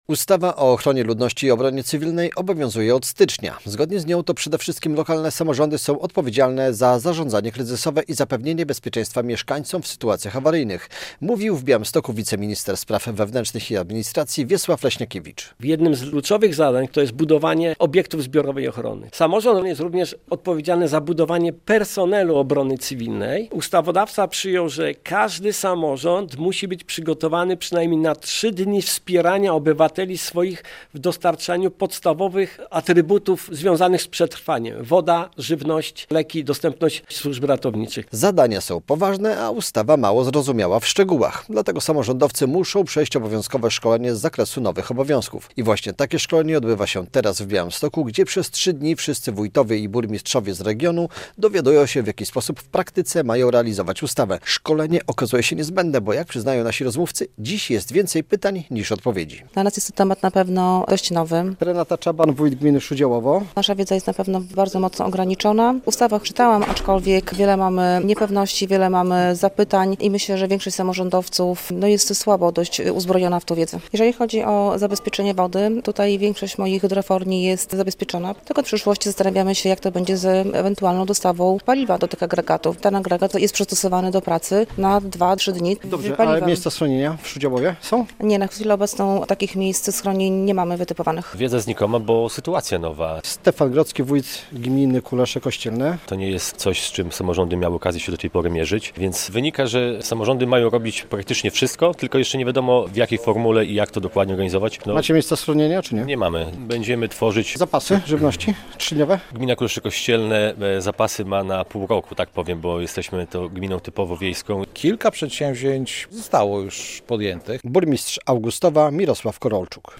W Białymstoku rozpoczęło się szkolenie z zakresu ochrony ludności i obrony cywilnej dla burmistrzów i wójtów - relacja